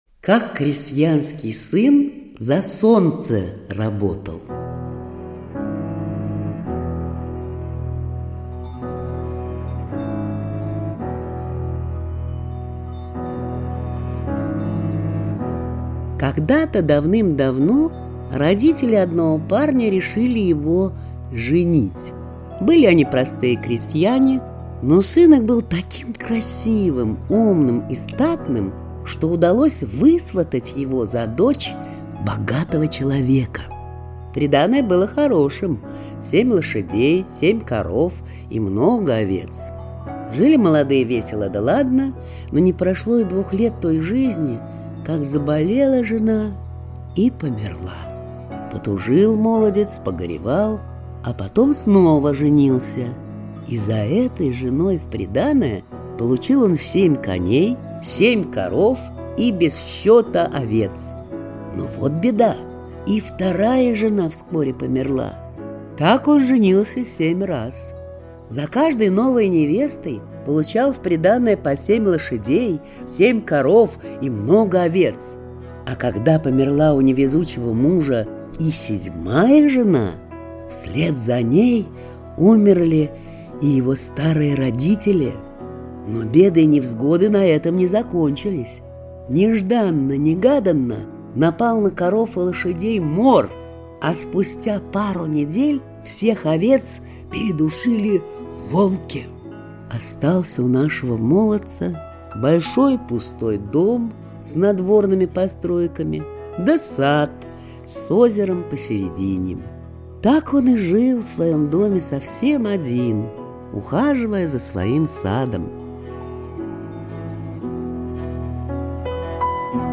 Как крестьянский сын за Солнце работал - чувашская аудиосказка. Крестьянский сын женился на дочери богатого человека и взял приданое...